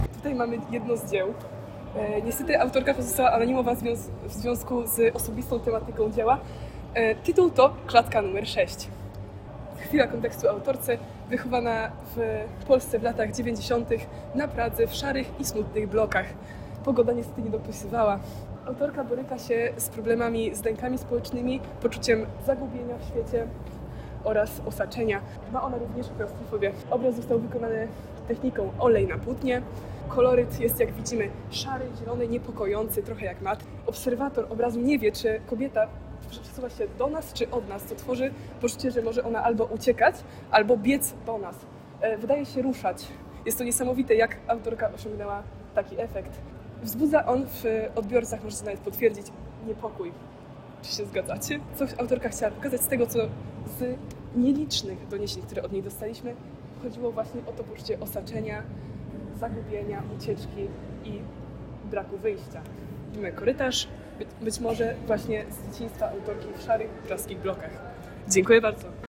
Moving Image. Intervention: Audioguide in Polish